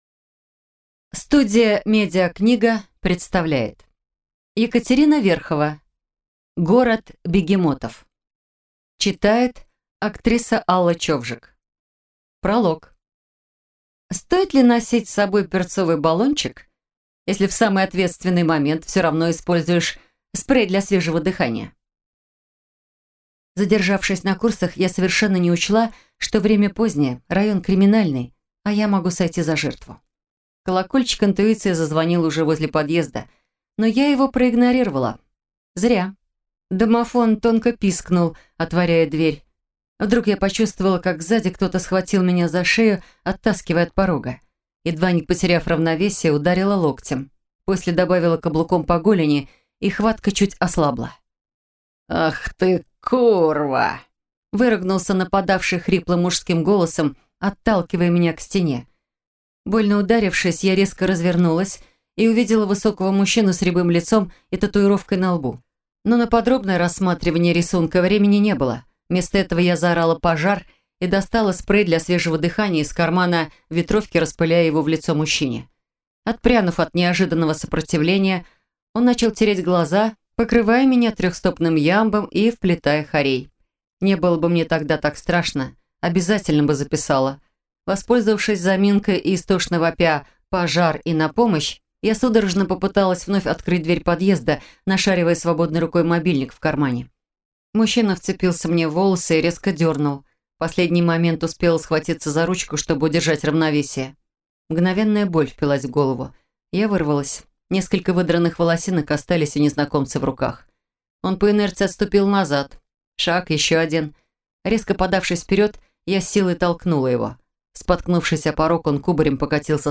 Аудиокнига Город бегемотов | Библиотека аудиокниг